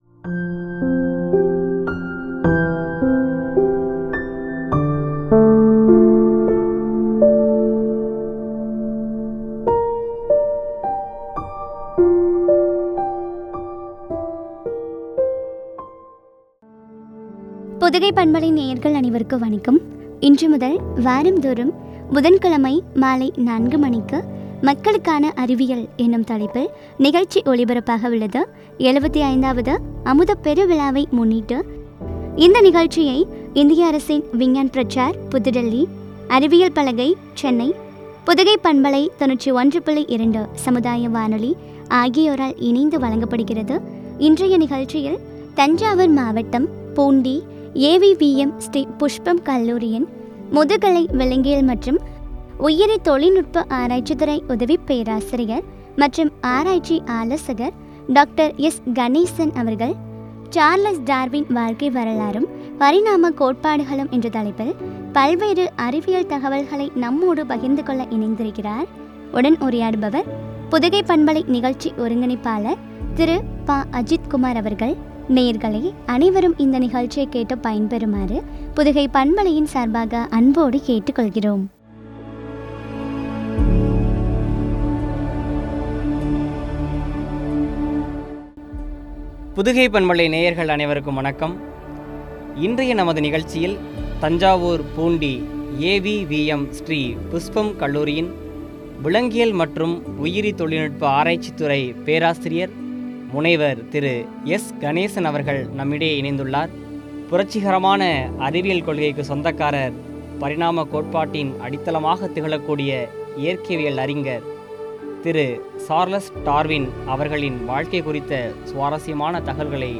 பரிணாம கோட்பாடும் என்ற தலைப்பில் வழங்கிய உரையாடல்.